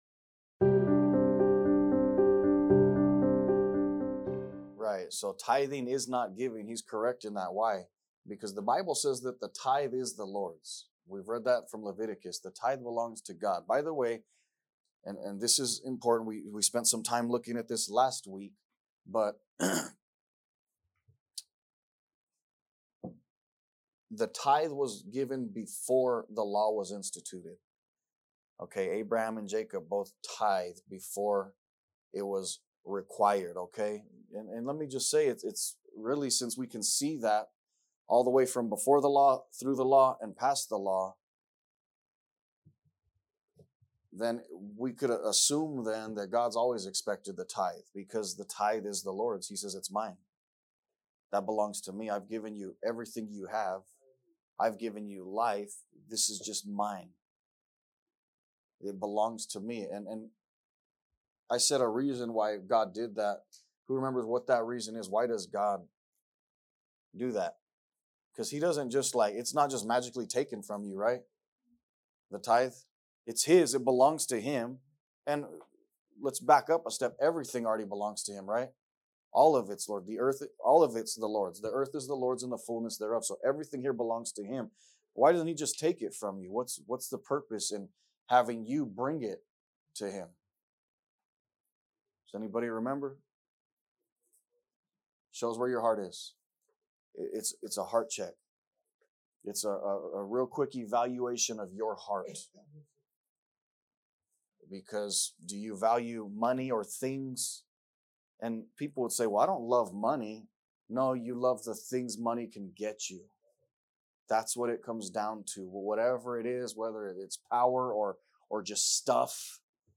A message from the series "Keys To A Growing Faith."